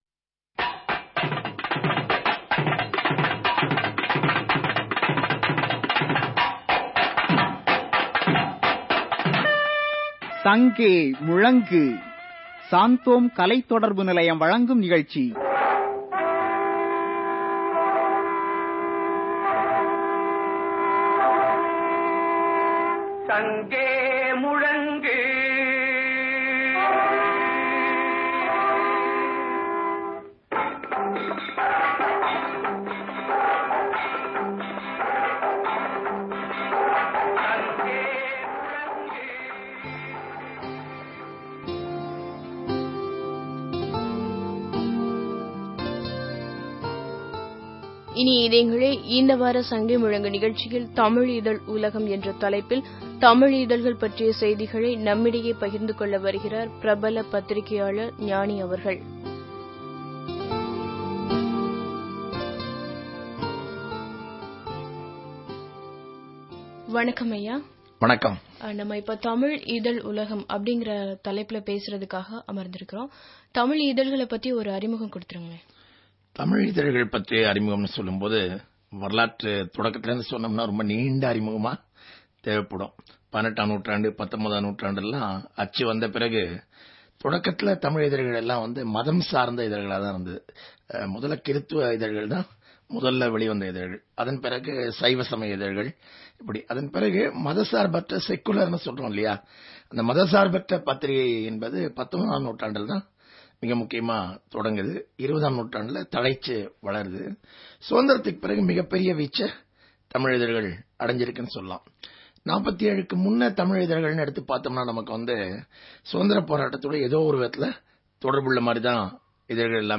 Directory Listing of mp3files/Tamil/People's Voice (SANGEA MUZHNGU)/Gnani Interview/ (Tamil Archive)